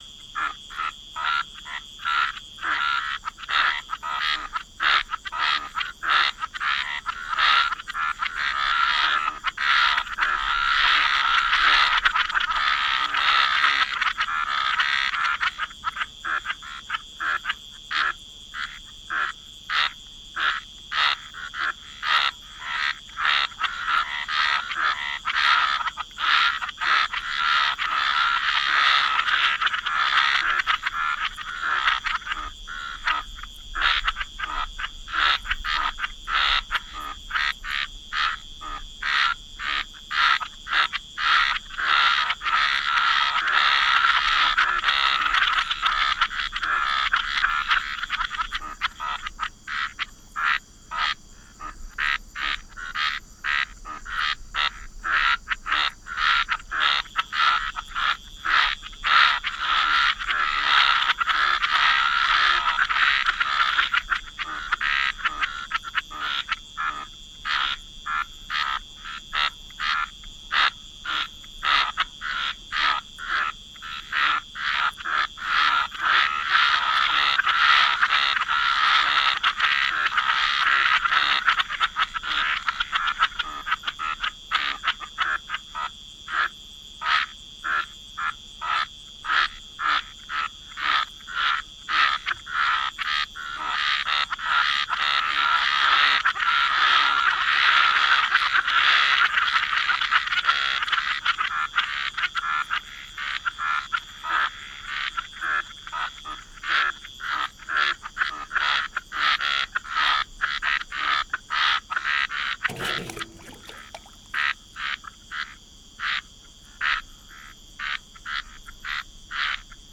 forest-night-4.ogg